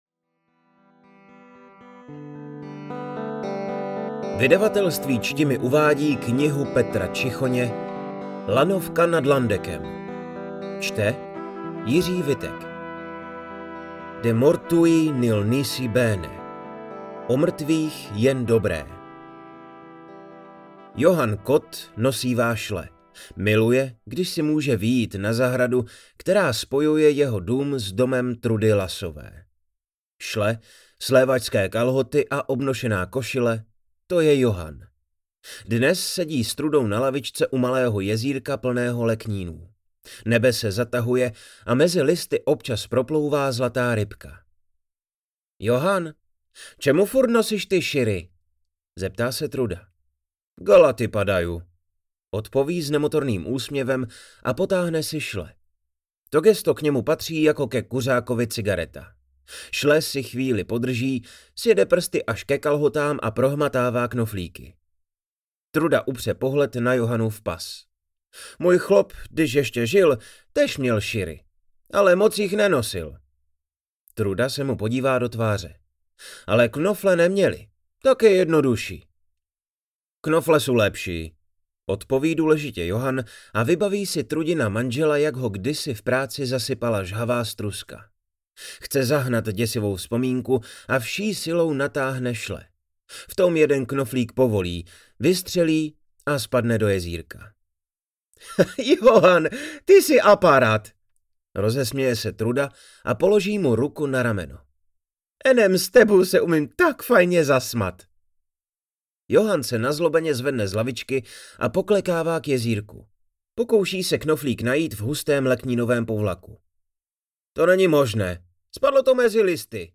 Rázovitý svět Hlučínska přibližuje i prajzský dialekt.